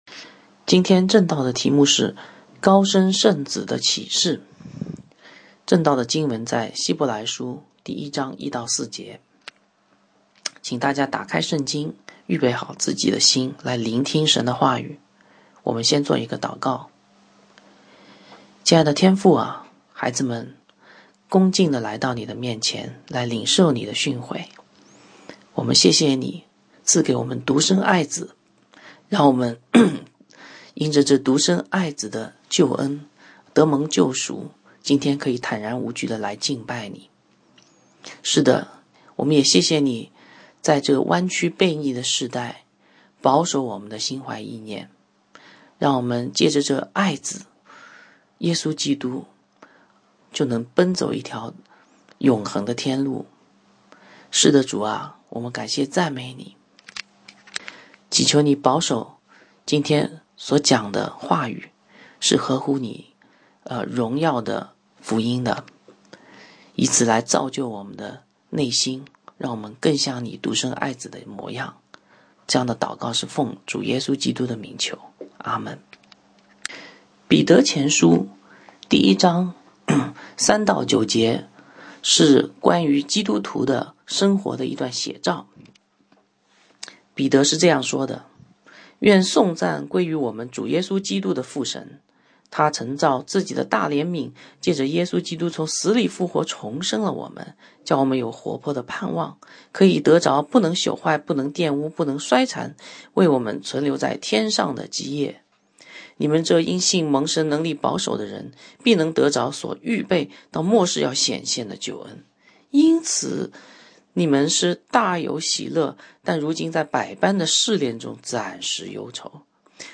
讲道